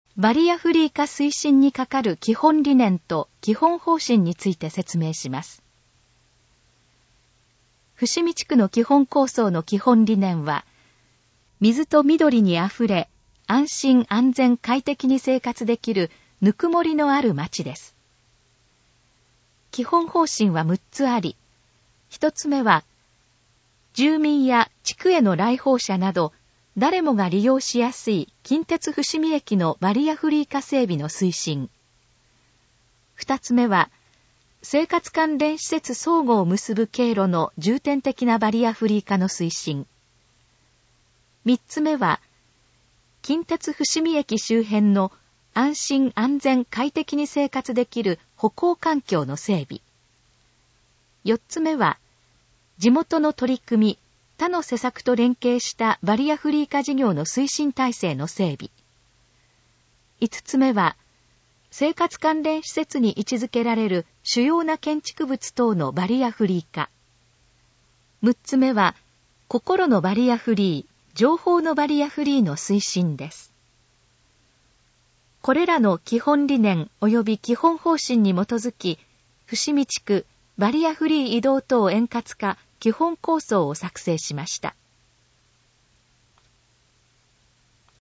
このページの要約を音声で読み上げます。
ナレーション再生 約344KB